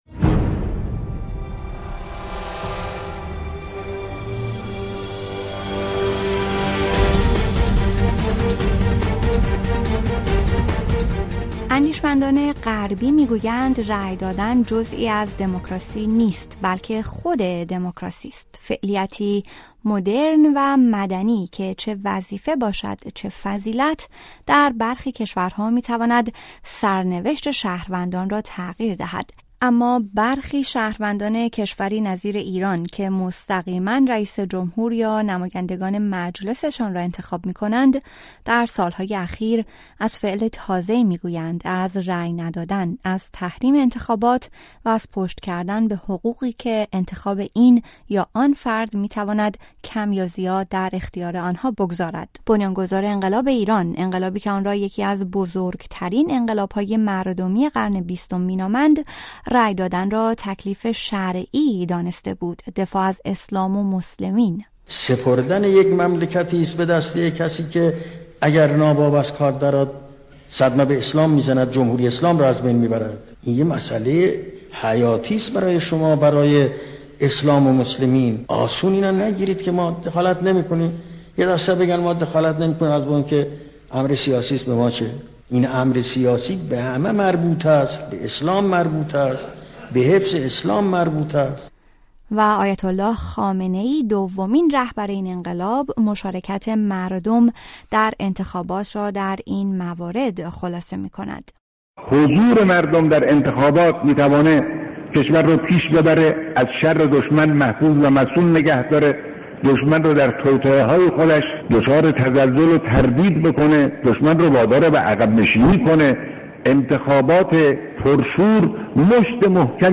رأی دادن یا تحریم از نگاه کارشناسان/ گزارش